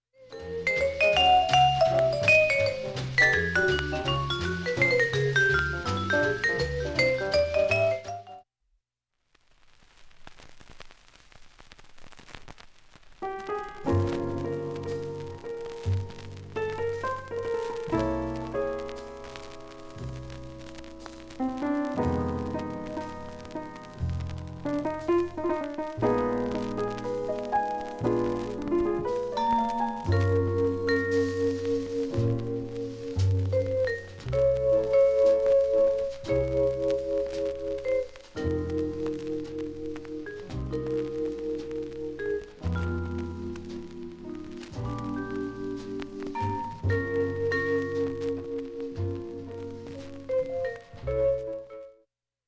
音質良好全曲試聴済み。
A-1中盤にかすかなプツが３回出ます。
６回までのかすかなプツが２箇所
３回までのかすかなプツが４箇所
単発のかすかなプツが４箇所
Repress, Mono, Deep Groove